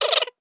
Laugh_v5_wav.wav